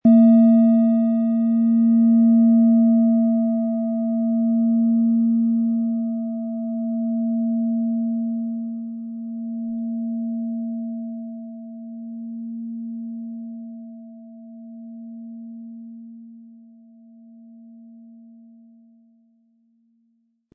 Wie klingt diese tibetische Klangschale mit dem Planetenton Hopi-Herzton?
PlanetentonHopi Herzton
HerstellungIn Handarbeit getrieben
MaterialBronze